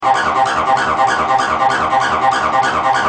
Sons et loops gratuits de TB303 Roland Bassline
Basse tb303 - 48